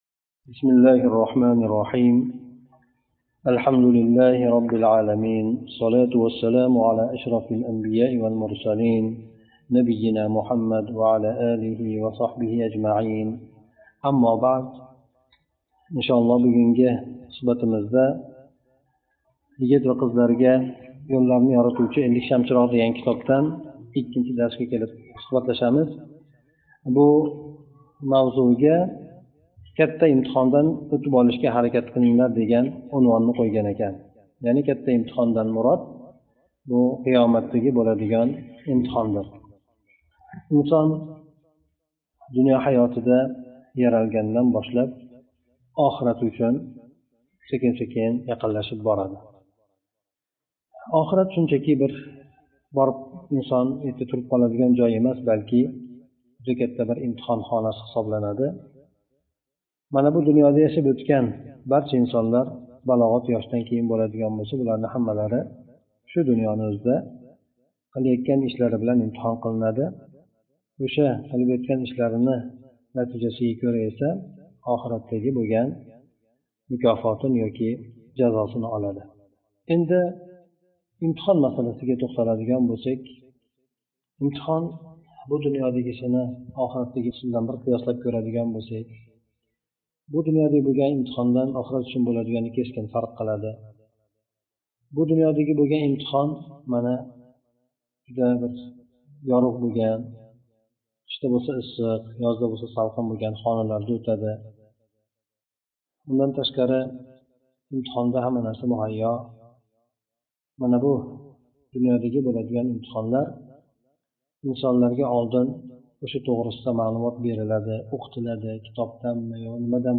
Ушбу маърузаларда китоб қисқача шарҳ қилинган.